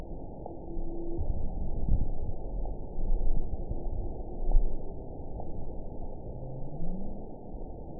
event 921760 date 12/18/24 time 23:04:06 GMT (4 months, 2 weeks ago) score 9.47 location TSS-AB03 detected by nrw target species NRW annotations +NRW Spectrogram: Frequency (kHz) vs. Time (s) audio not available .wav